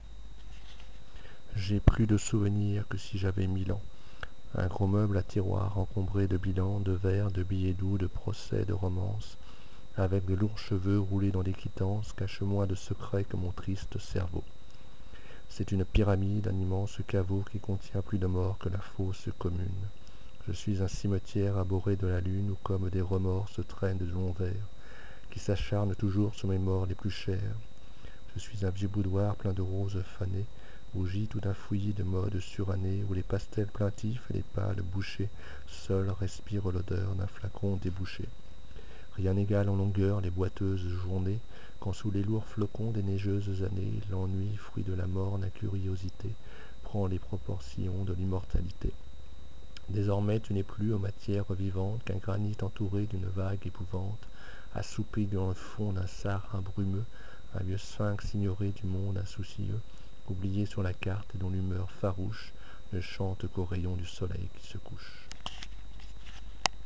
Billet sonore
Je m'entraine pour la Star Ac' mais je crains d'avoir de gros progrès à faire en diction.